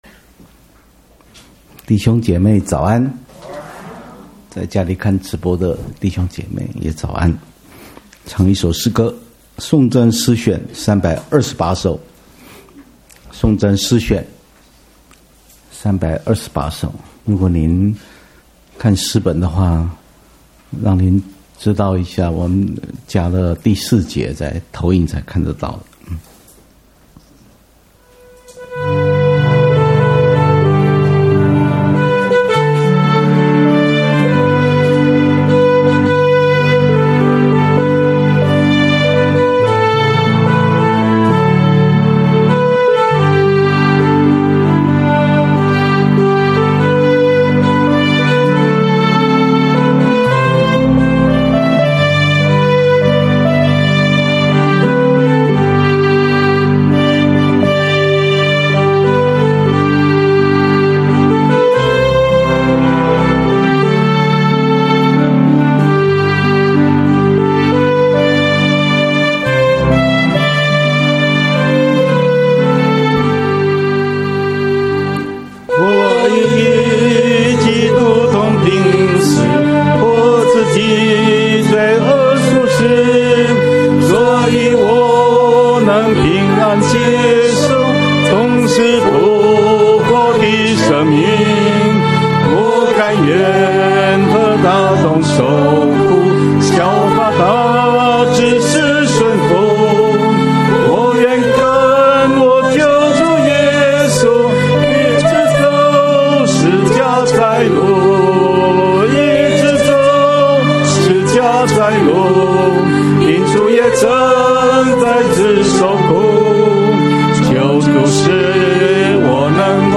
-講道